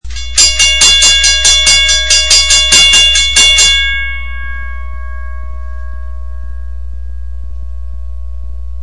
WRRS Bell #2
I can't get it to ring @ a normal WRRS/WCH ring rate because there is something wrong w/ the left magnet block. It likes to hold on to the swivel arm when it is in action giving the whole thing only half power.
CLICK THE ICON TO THE LEFT TO HEAR THE BELL IN ACTION.